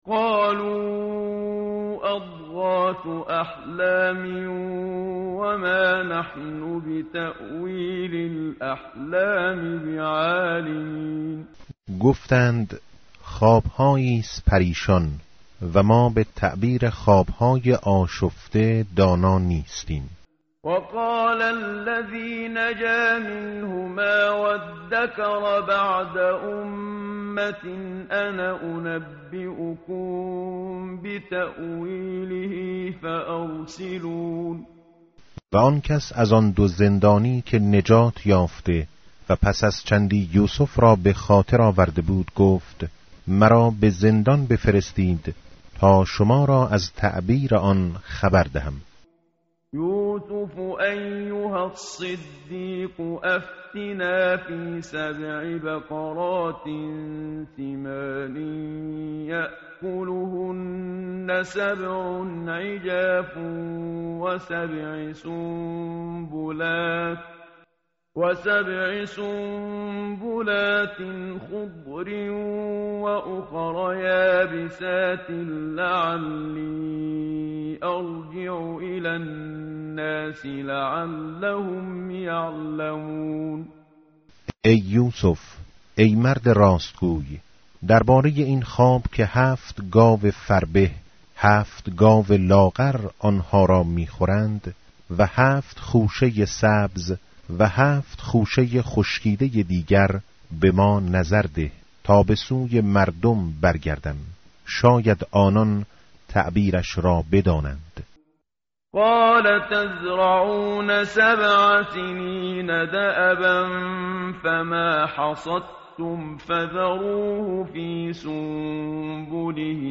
متن قرآن همراه باتلاوت قرآن و ترجمه
tartil_menshavi va tarjome_Page_241.mp3